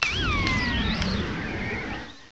sovereignx/sound/direct_sound_samples/cries/bramblin.aif at 2f4dc1996ca5afdc9a8581b47a81b8aed510c3a8